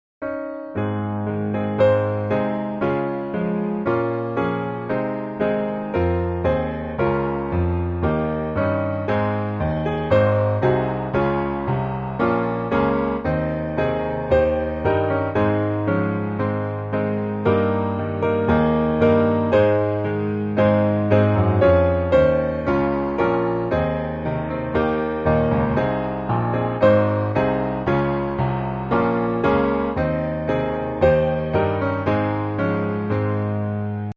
Ab Major